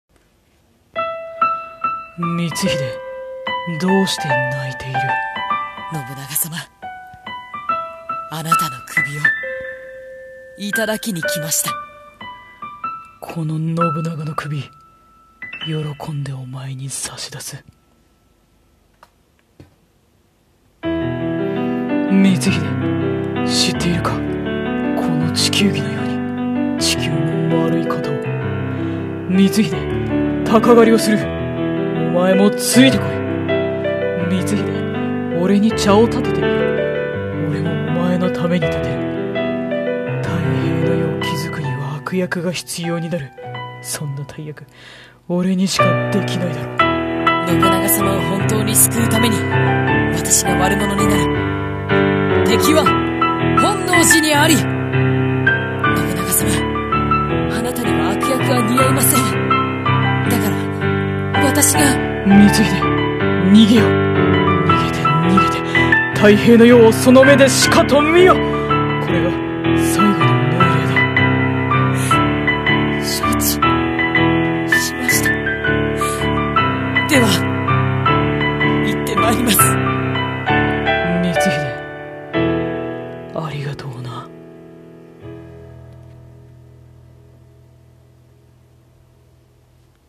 【声劇】敵は本能寺にあり